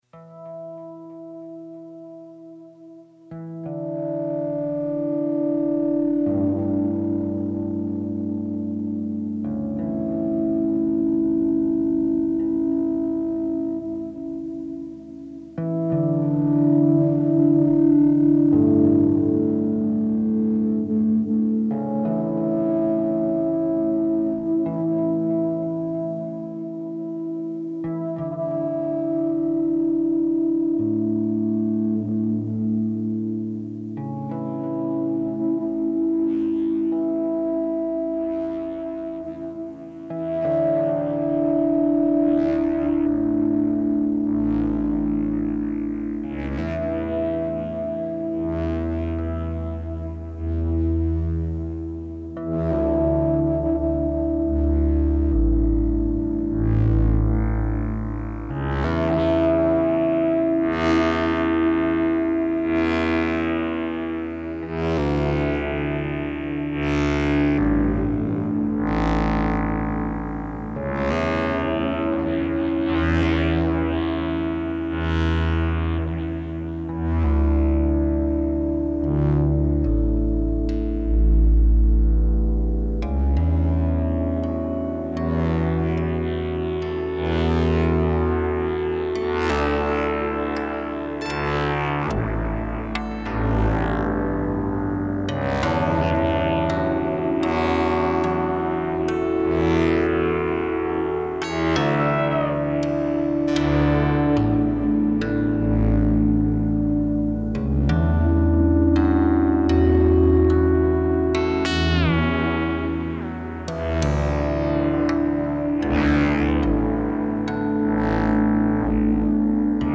Ramallah market, Palestine reimagined